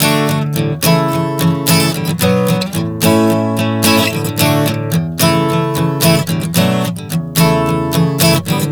Prog 110 D-A-Bm-A.wav